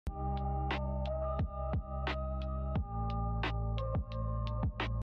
Drift Phonk. Taktik. Bass.